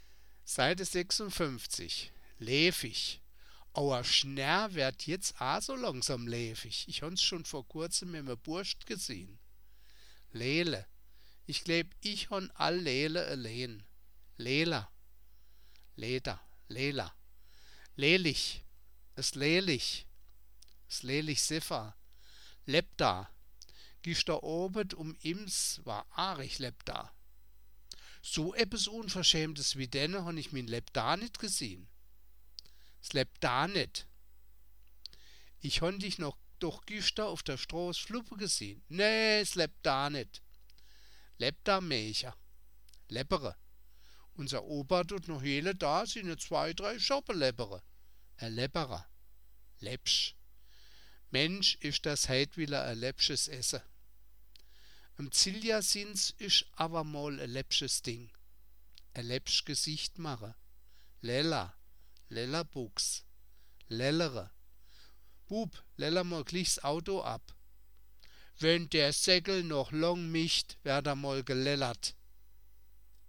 Das Wörterbuch der Ensheimer Mundart, Band I. Ensheim-Saar 1975